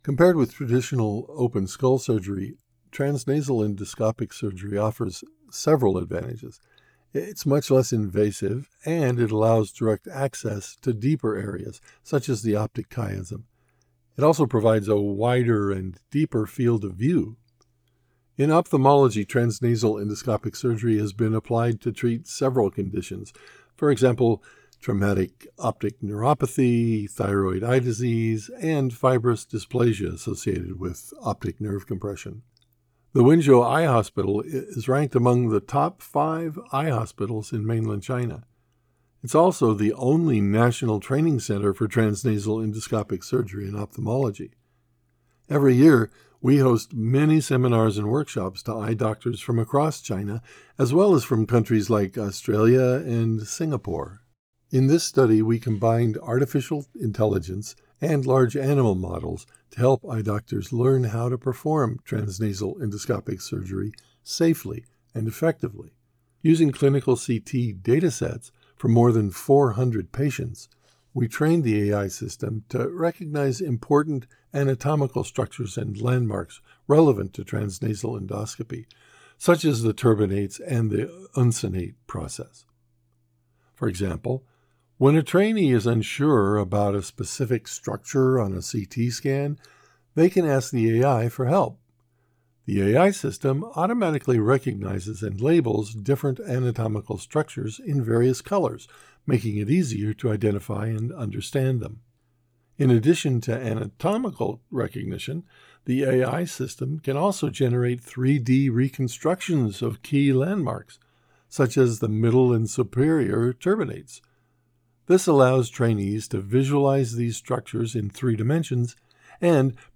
Medical Presentation
My delivery is sincere, friendly, believable, and natural—the qualities modern projects demand.